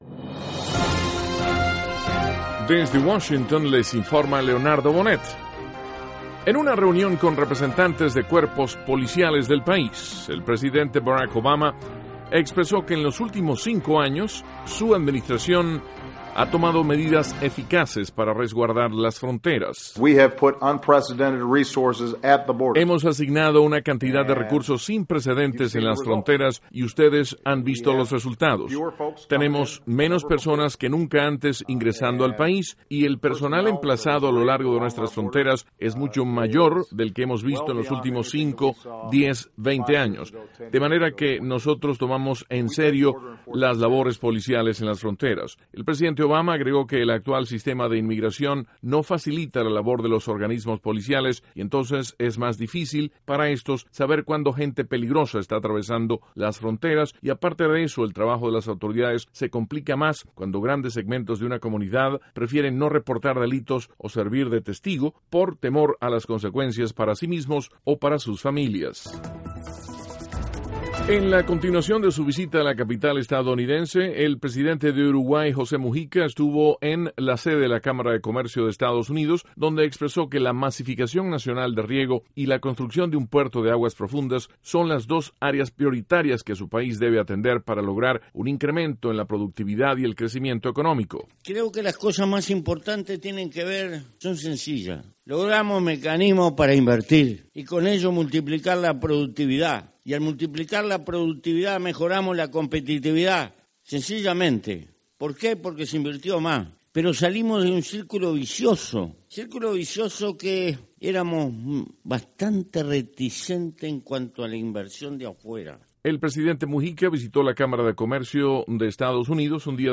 Duración: 3:13 Contenido: El presidente Obama habla de inmigración ante organismos policiales. (Sonido Obama) Continuando su visita a la capital estadounidense, el Presidente de Uruguay, José Mujica, habla ante la Cámara de Comercio de Estados Unidos. (Sonido – Mujica) La vocera del Departamento de Estado, Jen Psaki, responde a criticas de legisladores estadounidenses sobre la lentitud del proceso de imponer sanciones a funcionarios venezolanos.